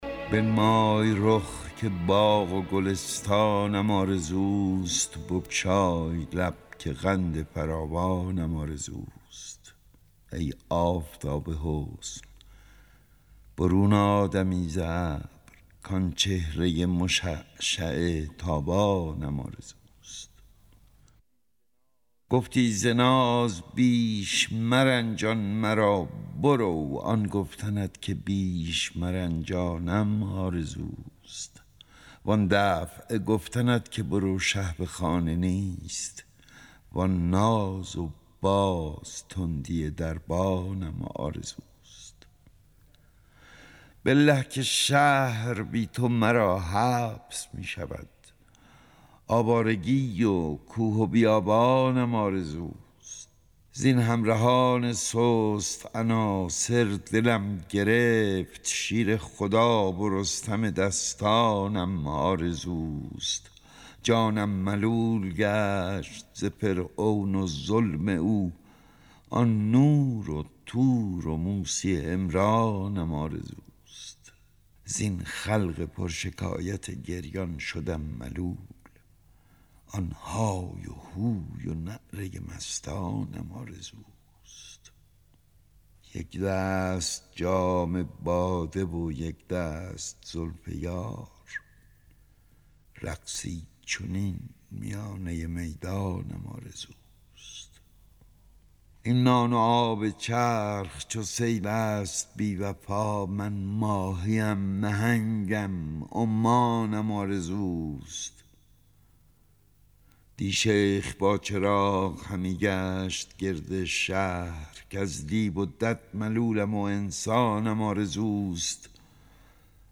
دانلود دکلمه بنمای رخ که باغ و گلستانم آرزوست با صدای احمد شاملو
گوینده :   [احمد شاملو]